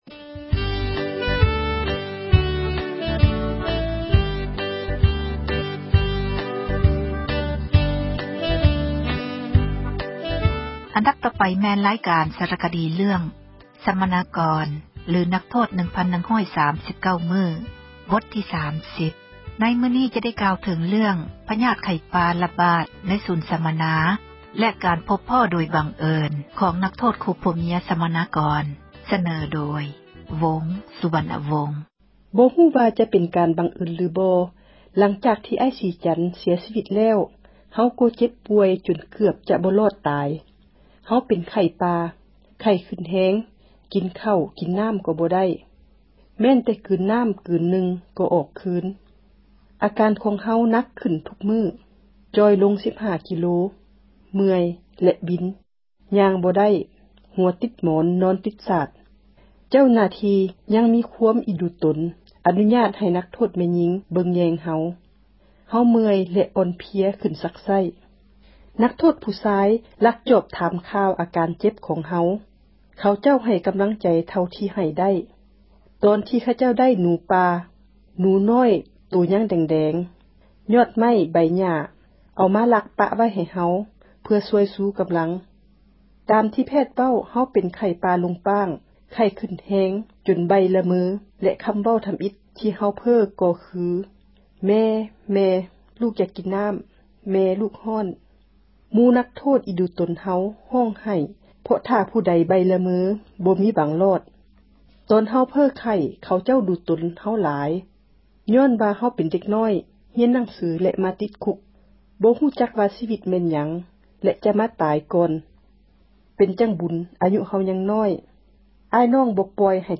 ຣາຍການ ສາຣະຄະດີ ເຣຶ້ອງ “ສັມມະນາກອນ ຫຼື ນັກໂທດ 1,139 ມື້” ບົດທີ່ 30. ໃນມື້ນີ້ ຈະໄດ້ກ່າວ ເຖິງເຣື້ອງ ພຍາດ ໄຂ້ປ່າ ຣະບາດ ໃນສູນ ສັມມະນາ ແລະ ການພົບພໍ້ ໂດຍ ບັງເອີນ ຂອງ ນັກໂທດ ຄູ່ຜົວເມັຍ ສັມມະນາກອນ.